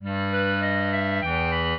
clarinet
minuet2-3.wav